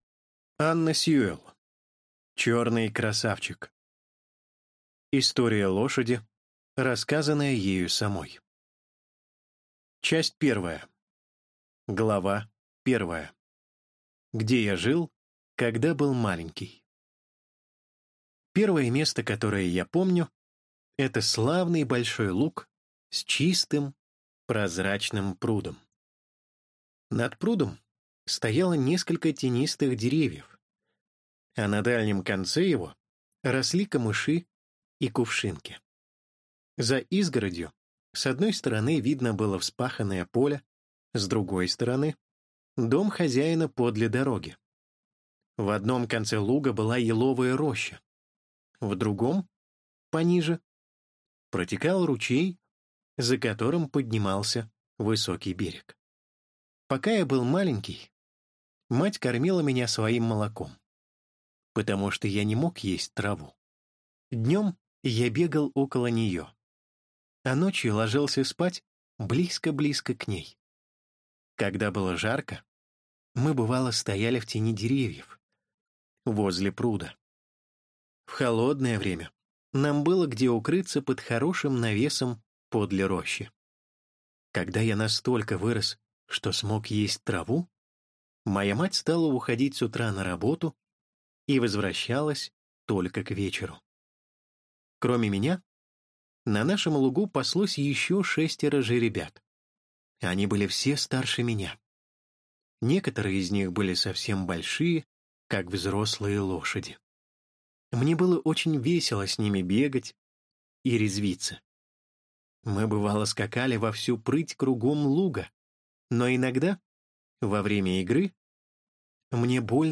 Аудиокнига Черный красавчик | Библиотека аудиокниг